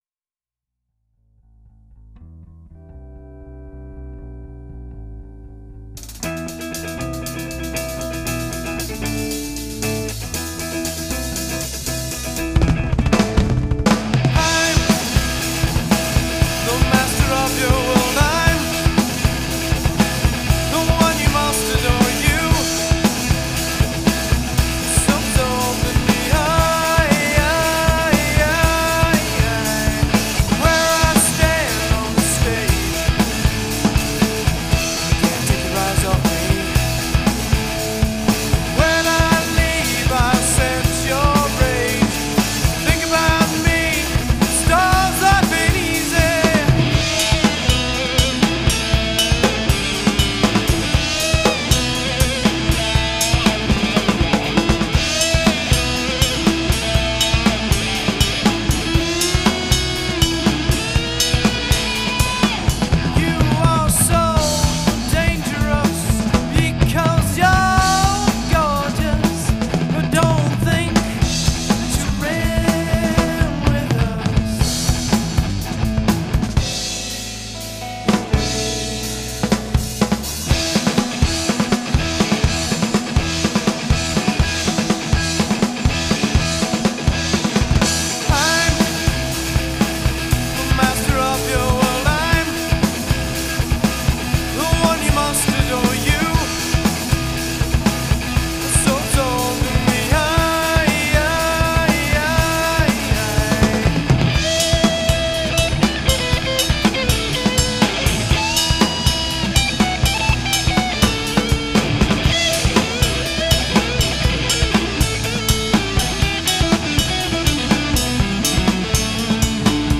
Vocals
Guitars
Bass
Drums